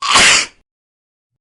Free SFX sound effect: Sneeze 1.
Sneeze 1
Sneeze 1.mp3